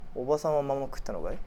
Aizu Dialect Database
Type: Yes/no question
Final intonation: Rising
Location: Aizuwakamatsu/会津若松市
Sex: Male